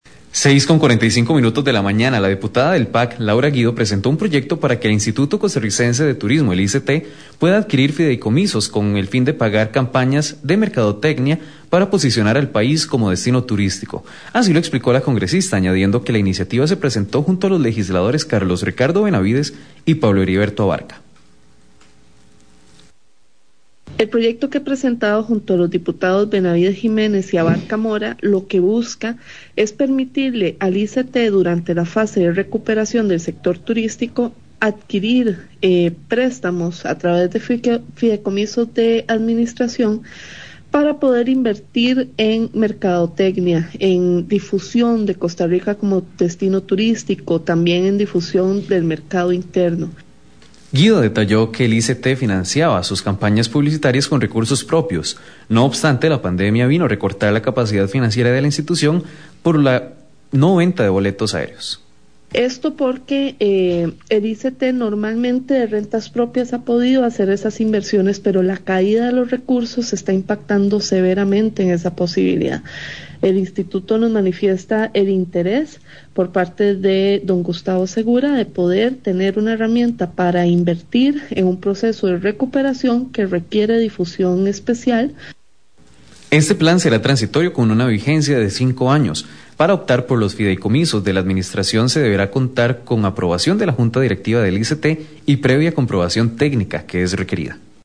Vocera en la nota: diputada Laura Guido